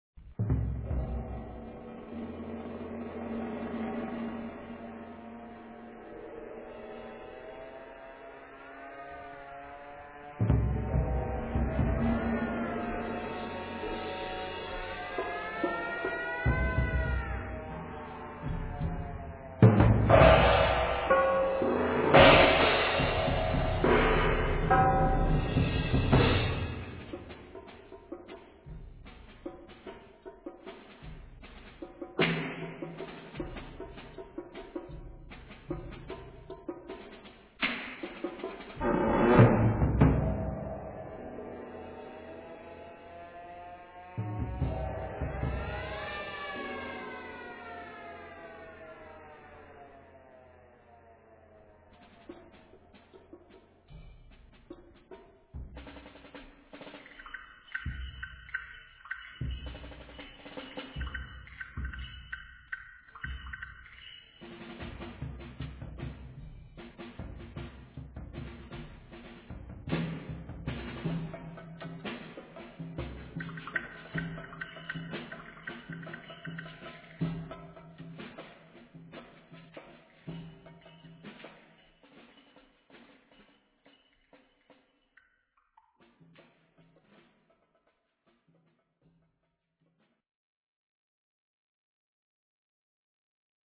escrita per a 37 instruments de percussi� i sirenes.